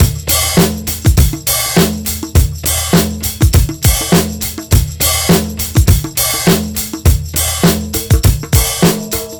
TR BEAT 1 -R.wav